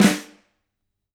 Index of /musicradar/Snares/Ludwig A
CYCdh_LudFlamA-05.wav